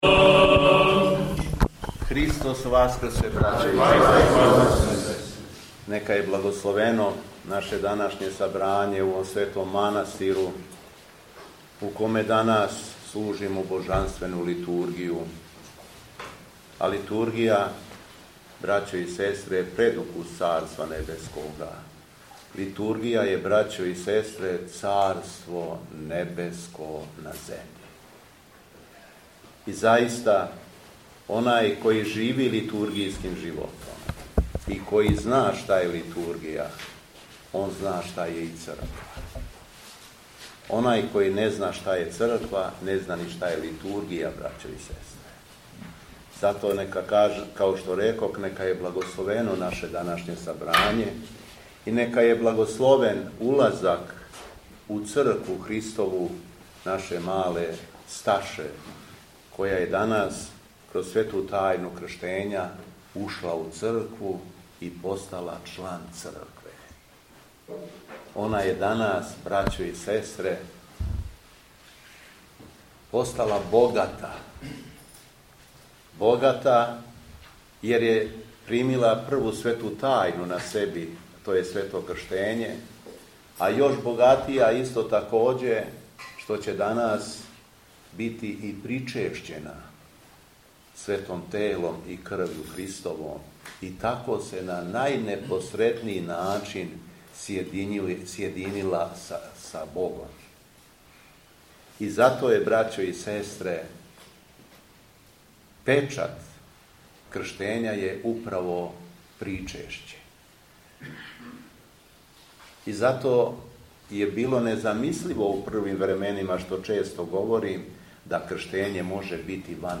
Беседа Његовог Високопреосвештенства Митрополита шумадијског г. Јована
У уторак, 21. априла 2026. године, у другој недељи по Васкрсењу, Његово високопреосвештенство митрополит шумадијски господин Јован служио је Свету Литургију у храму Светог великомученика Георгија у манастиру Ћелије.